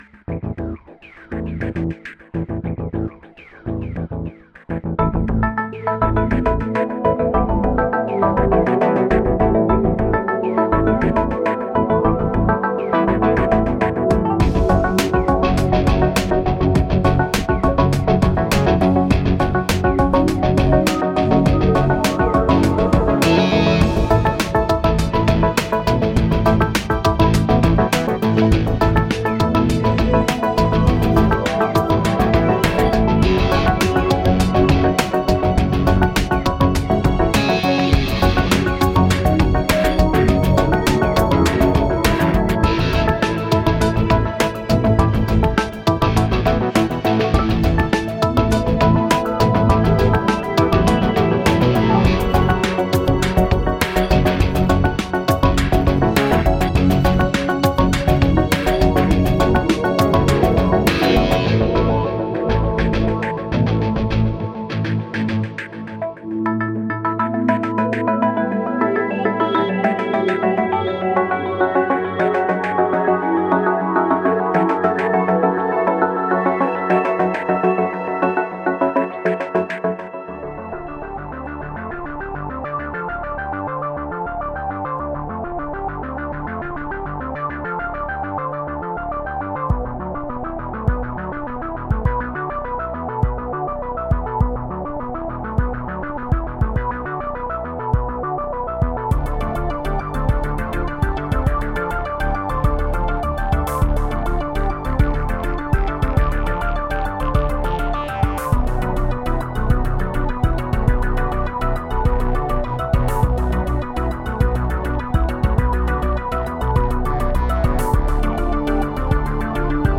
An electronic track that blends into another...